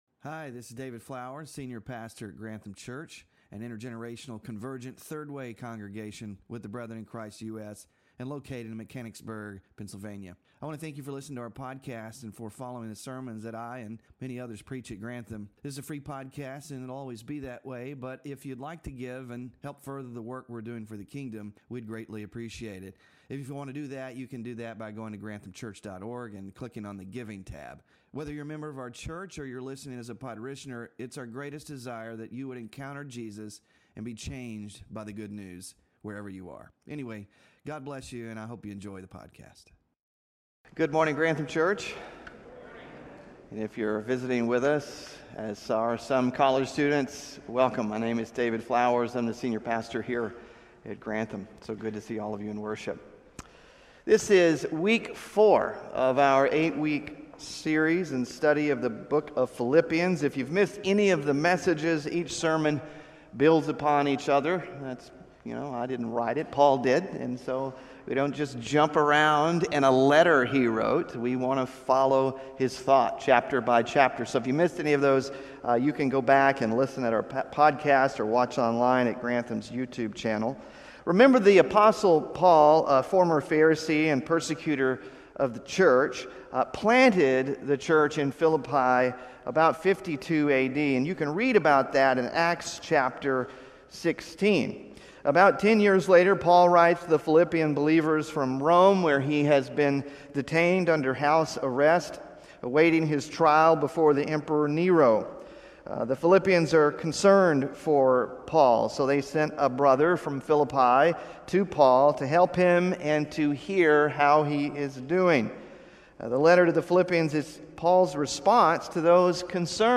PHILIPPIANS SERMON SLIDES (4TH OF 8 IN SERIES) SMALL GROUP DISCUSSION QUESTIONS (9-1-24) BULLETIN (9-1-24)